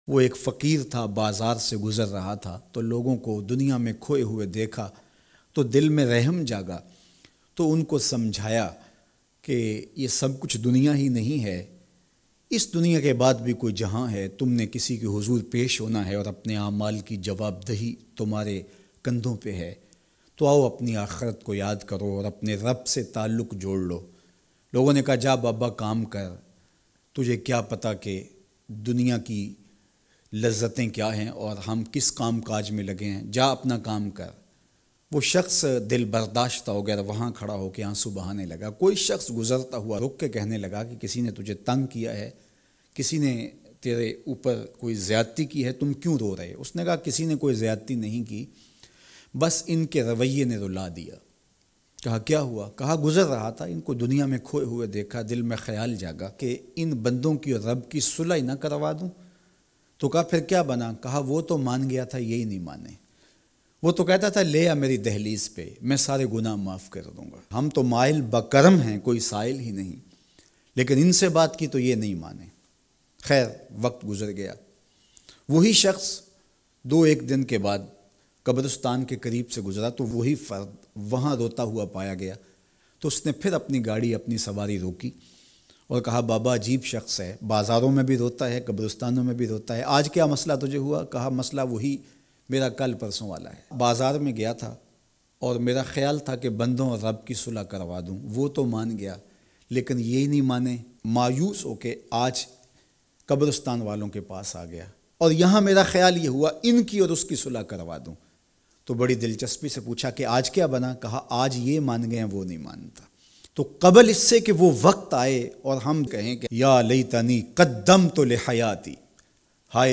Abhi Maazrat Kr Lo Warna Buhat Pachtao gay Bayan MP3 Download